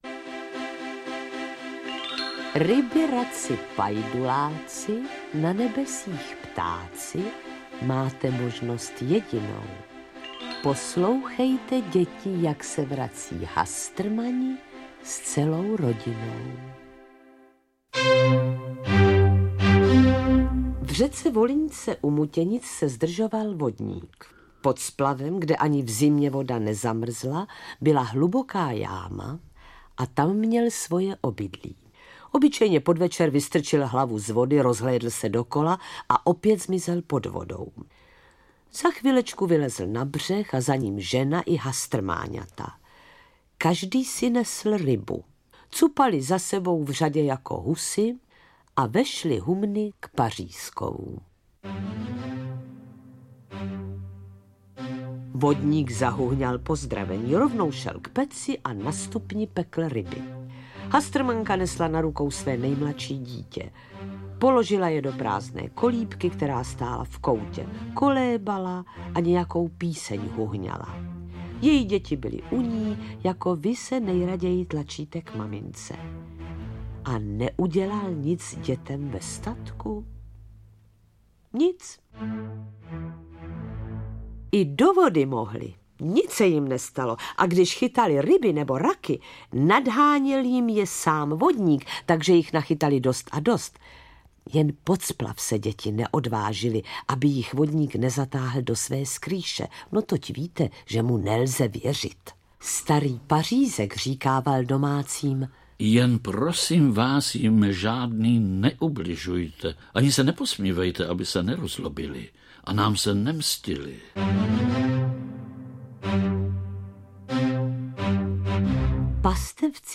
Interpreti:  Vlastimil Brodský, Jiřina Jirásková
AudioKniha ke stažení, 7 x mp3, délka 1 hod. 5 min., velikost 59,5 MB, česky